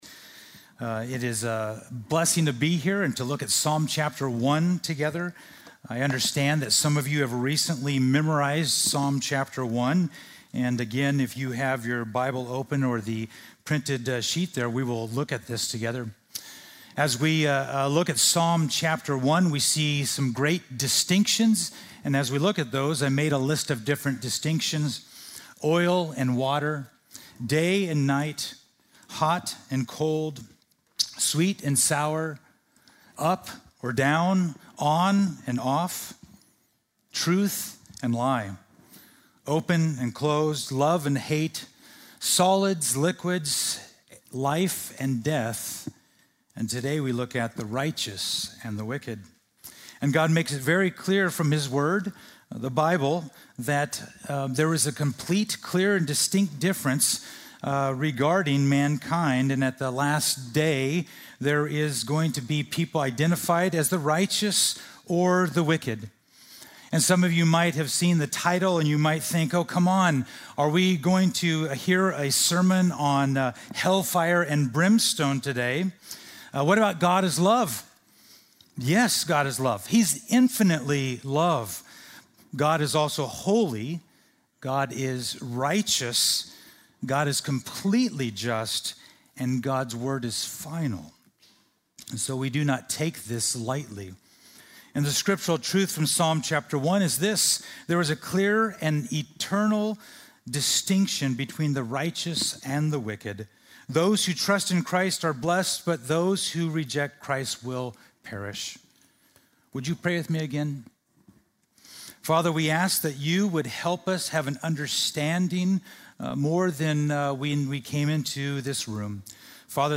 Home \ Sermons \ Other Sermons \ Psalm 1 Psalm 1 by Guest Preacher